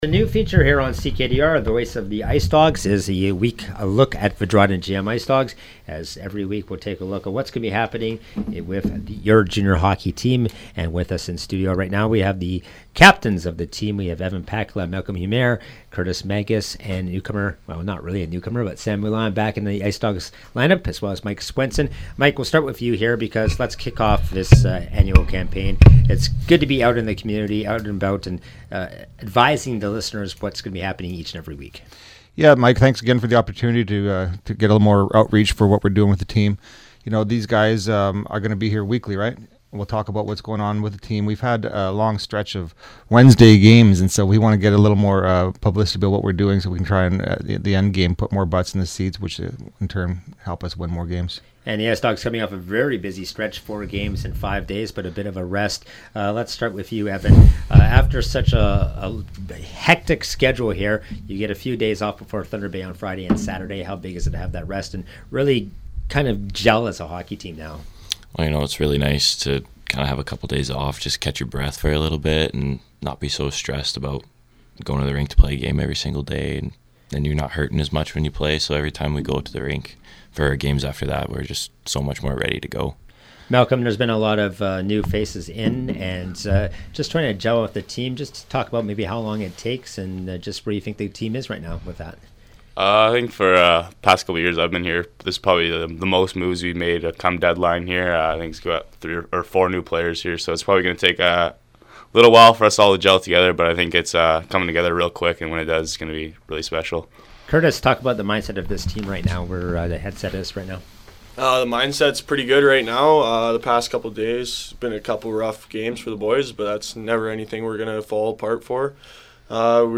Every Monday, we will talk to team reps and players on what is happening on and off the ice with the local junior hockey team.